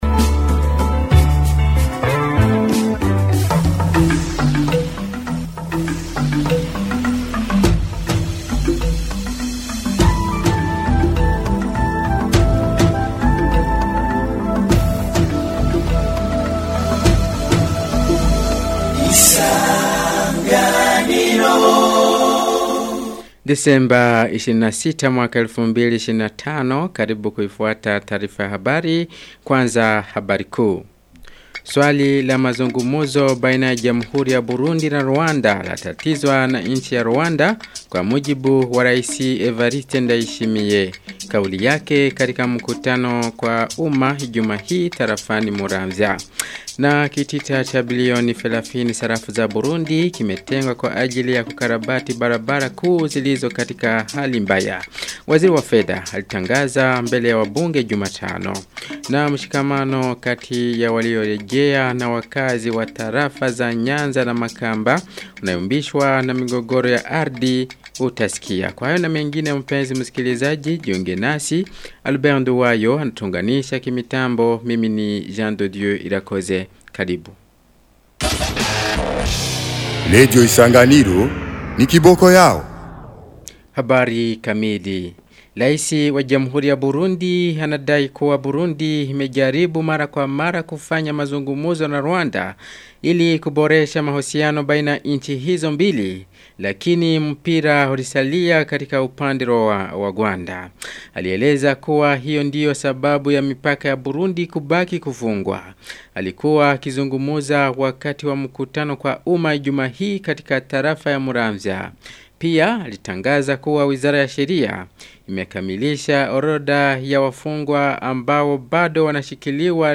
Taarifa ya habari ya tarehe 26 Disemba 2025